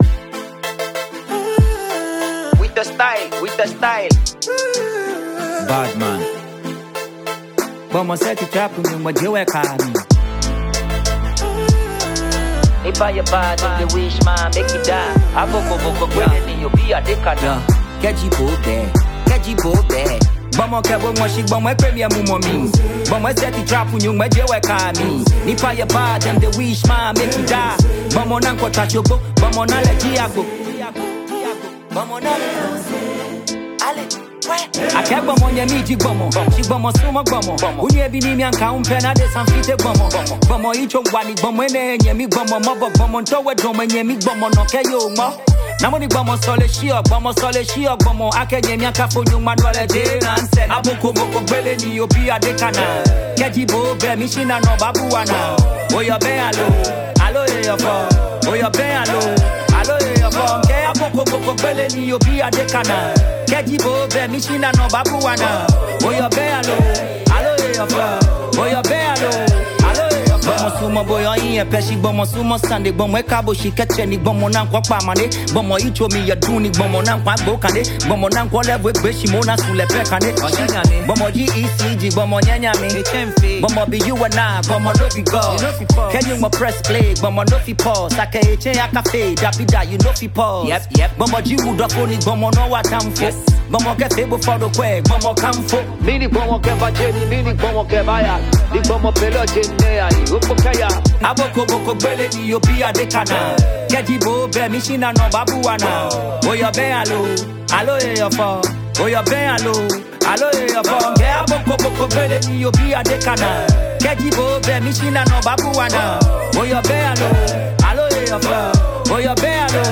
Veteran Ghanaian rapper